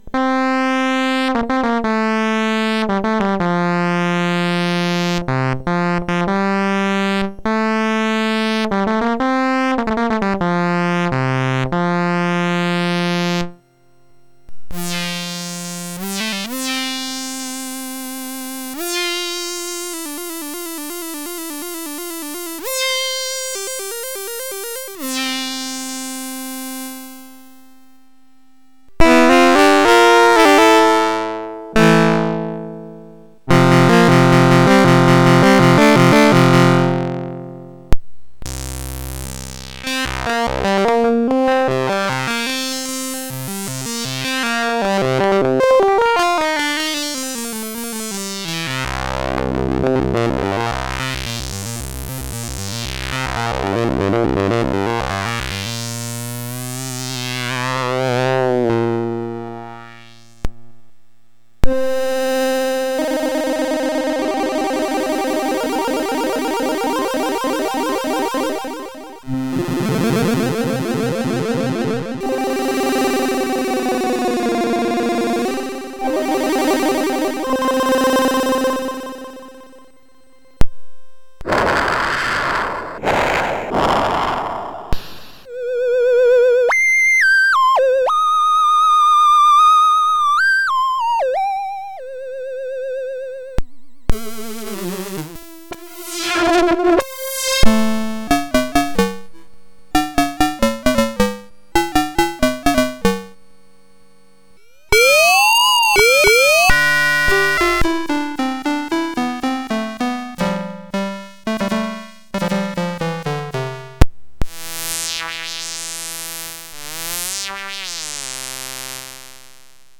Un petit exemple pour que vous puissiez vous faire une id�e du son, rien de musical juste un zapping rapide des premiers presets. Le son est bien l� !
C'est cool, mais j'ai l'impression qu'il y a un Aliasing de folie, c'est ton contr�leur midi, ou l'Hardsid elle m�me qui fait �a?Sinon, c'est COOL, et je crois que j'en REVEUX une !
Hardsid_Patch_Test.mp3